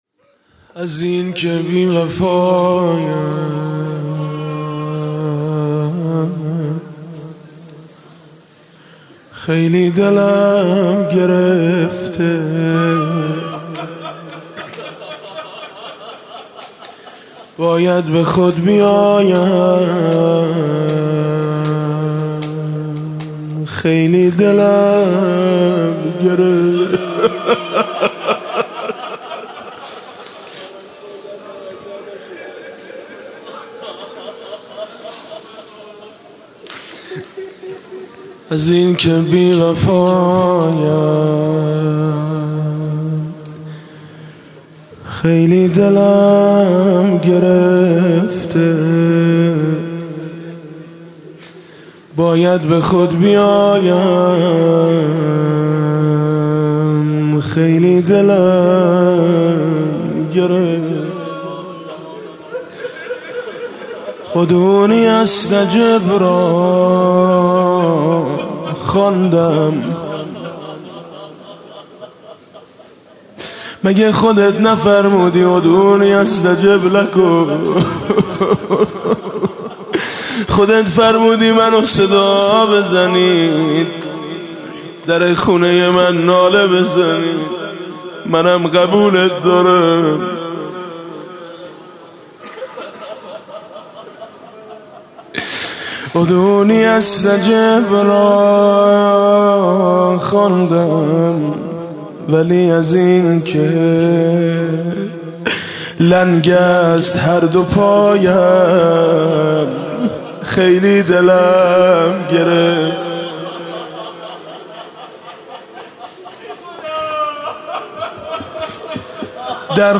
«شب نوزدهم» مناجات با خدا: خیلی دلم گرفته